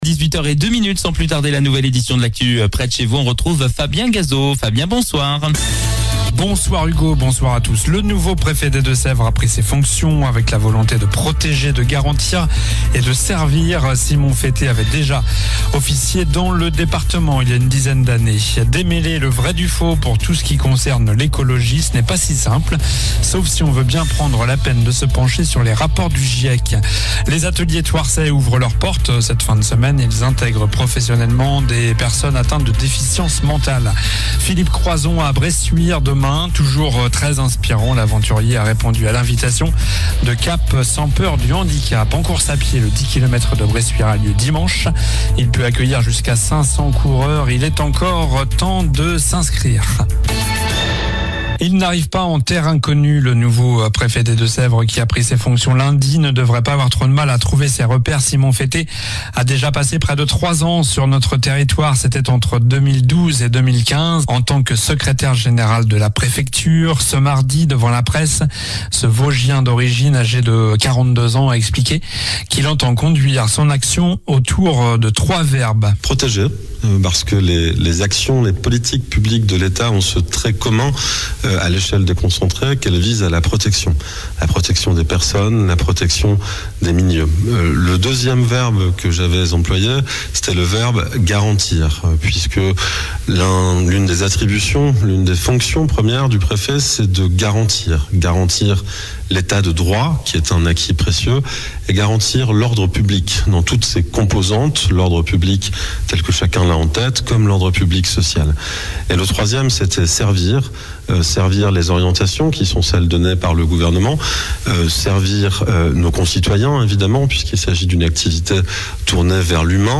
Journal du mercredi 09 avril (soir)